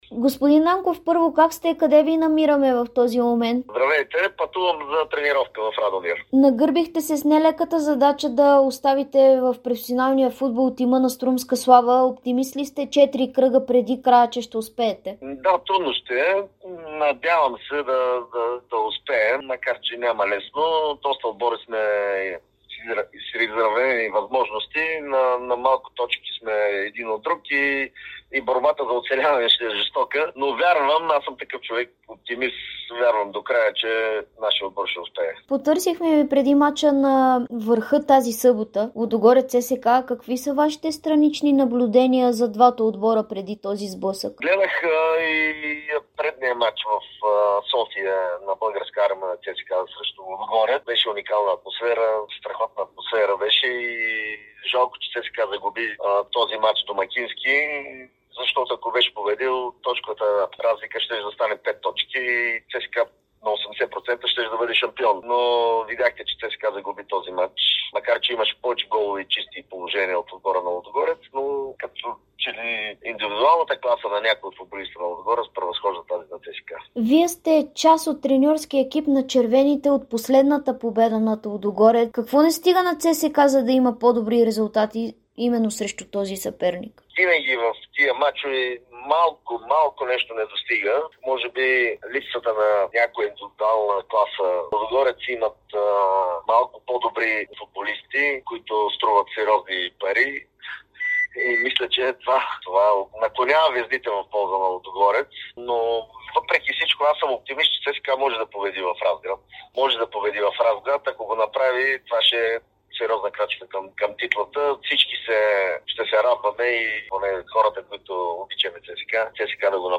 Старши треньорът на Струмска слава Анатоли Нанков коментира пред Дарик радио и dsport предстоящия мач между Лудогорец и ЦСКА. Той сподели и защо не е приел предложението да заработи в ЦСКА 1948.